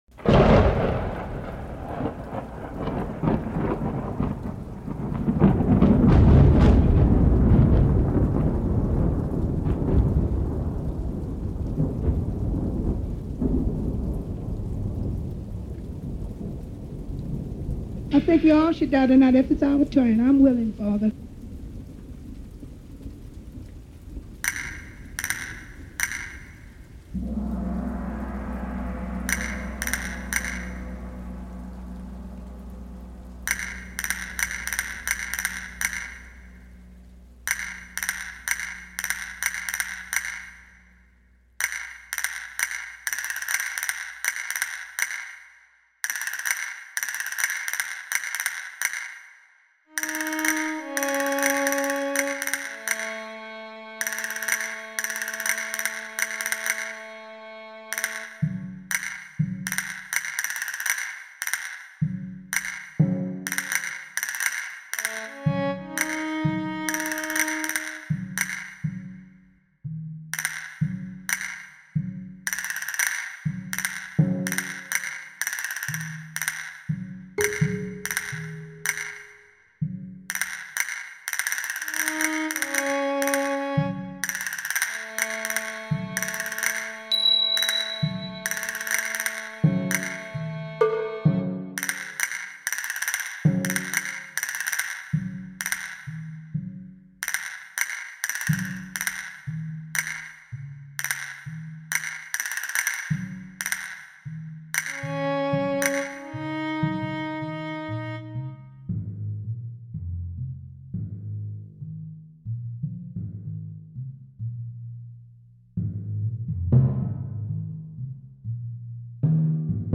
At that point, a Day of the Dead flamenco dance takes place around Jones’ body. Scored for orchestral percussion, Jonestown voices and solo violin.